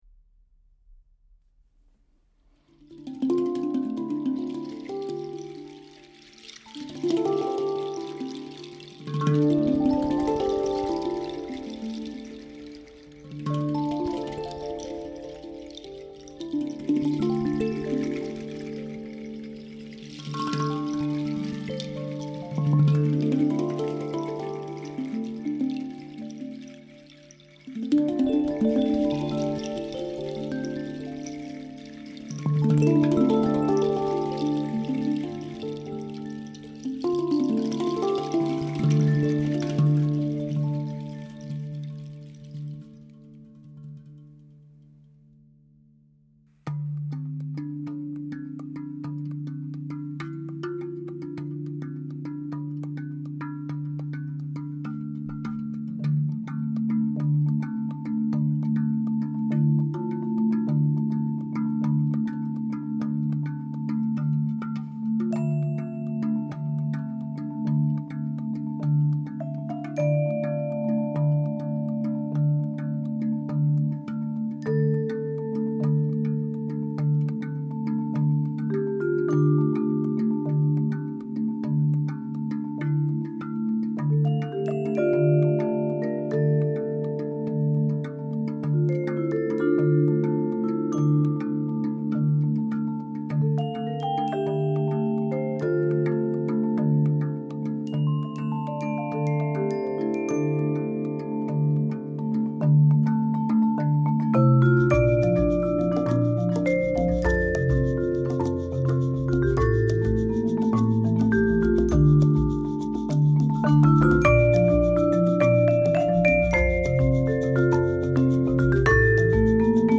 • Trio version
• 3 players
• Marimba & Vibraphone, Cello, Percussion